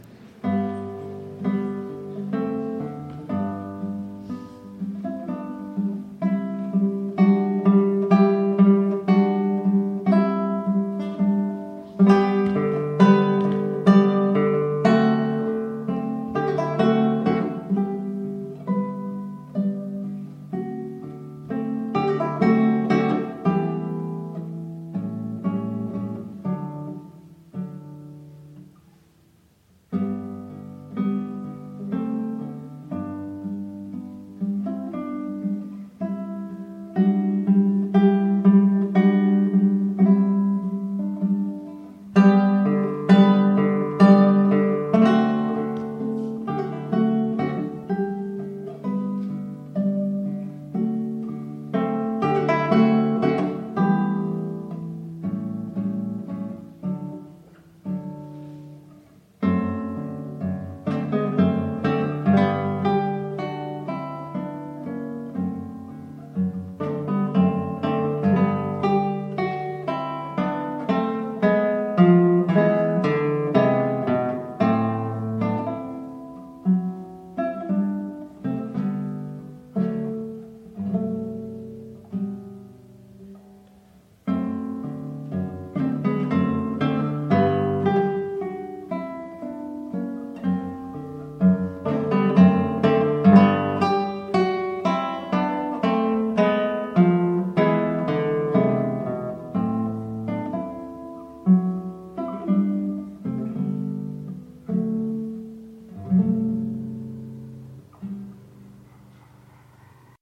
Guitar  (View more Advanced Guitar Music)
Classical (View more Classical Guitar Music)